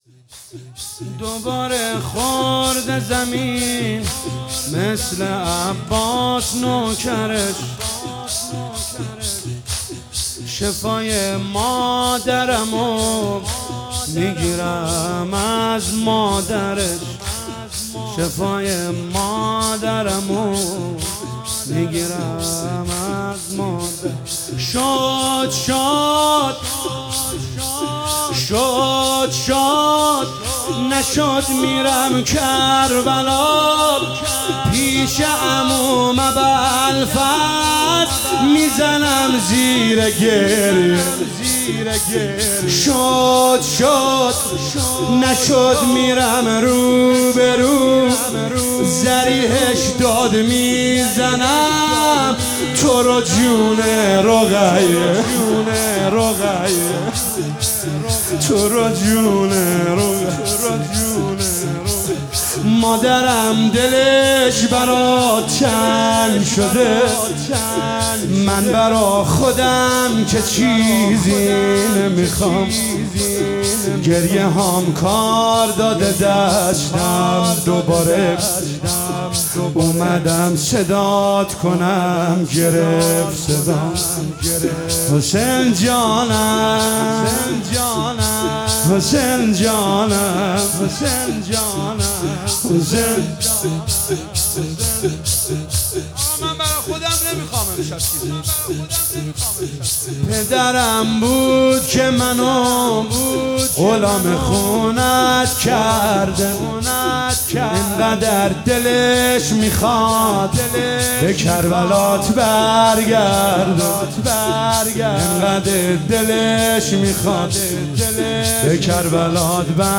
شور شب اول محرم 1446